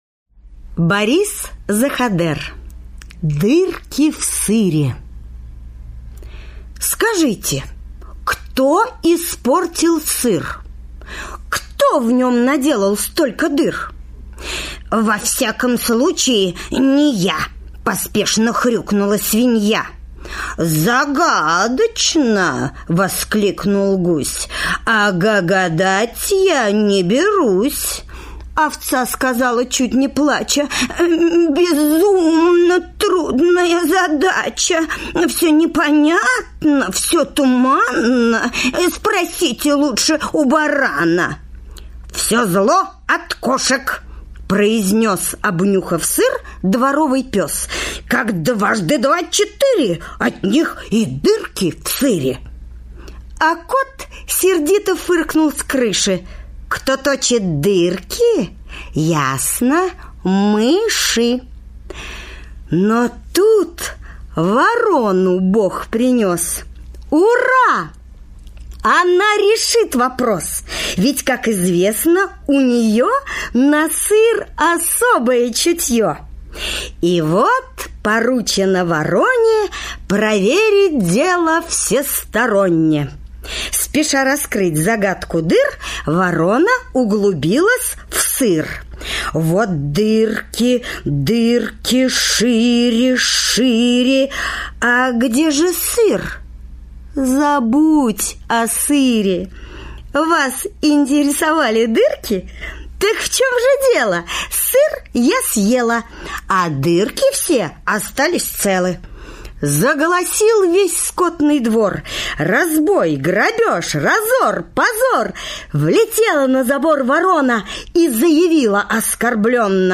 На данной странице вы можете слушать онлайн бесплатно и скачать аудиокнигу "Дырки в сыре" писателя Борис Заходер.